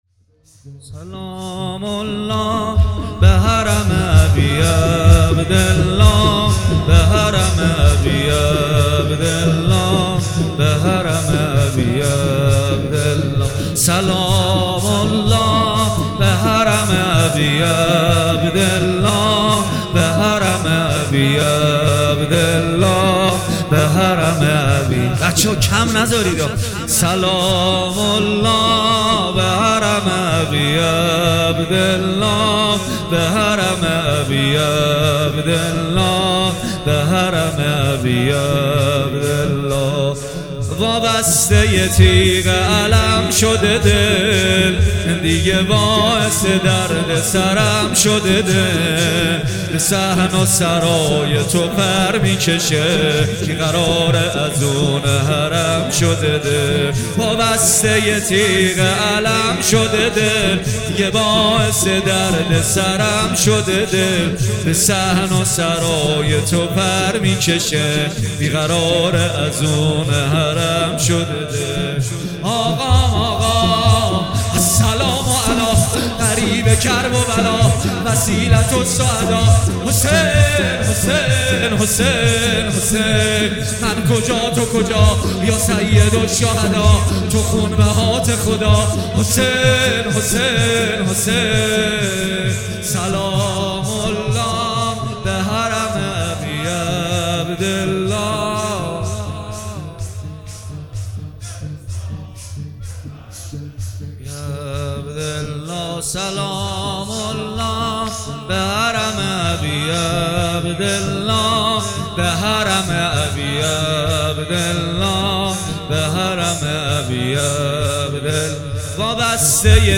مداحی کربلایی محمد حسین پویانفر دهه اول محرم 99
دانلود مراسم شب اول محرم ۹۹ به صورت یکجا